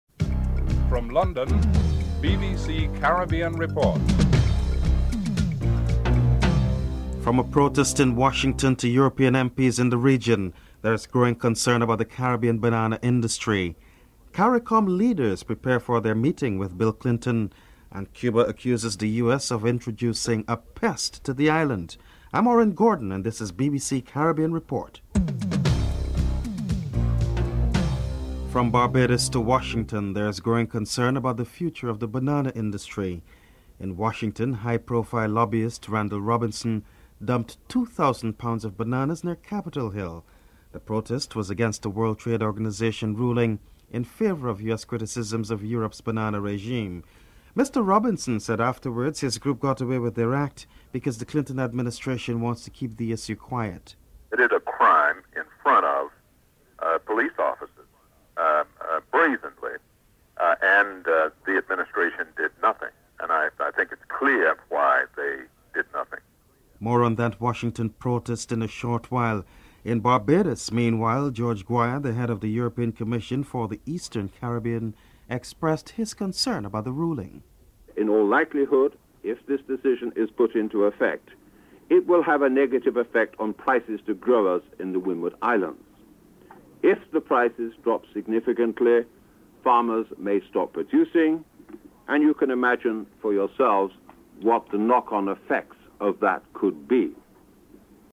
1. Headlines (00:00-00:28)
President Bill Clinton is interviewed (07:18-09:42)